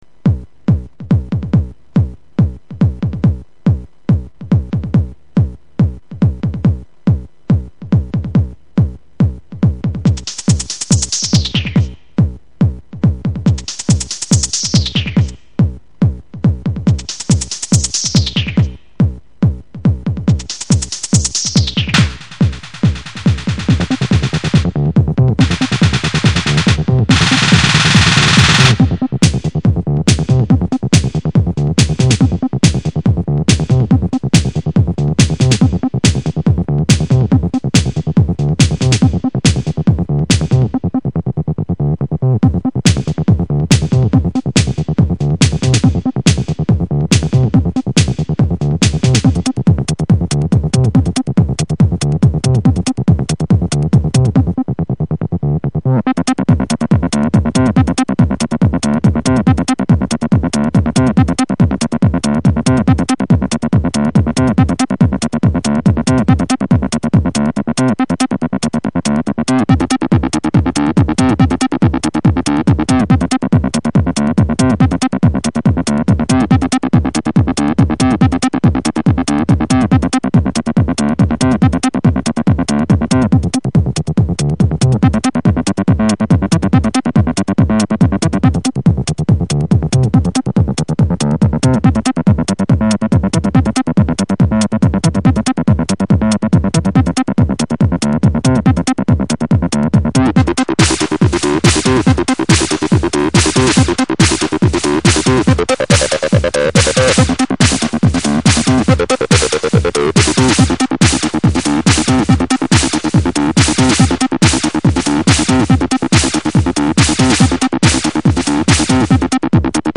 Hab heute in der Früh spontan ne Acidlivesession gemacht. Ist nicht sooo toll von den Übergängen her und Audioqualität ist wegen Server auch niedrig.
Rave-O-lution 309 (Drums,Percussion)
FreeBass-383/TB303Clone (Säure)
Virtualizer Pro DSP2024 (Distortion für 383)
Aber ich liebe es den Resonanceregler zu bearbeiten und den Filter "kreischen" zu lassen...
Hast Du die anderen Spuren gemutet oder so, oder ist wirklich nur Drum und diese Filterfolter :-) ?
Ich hab meine 4 Spuren Drums/Perc programmiert, den Synthi mit einem 16Step loopenden Pattern beschossen, die Spuren abwechselnd gemutet und an den Filtern gedreht.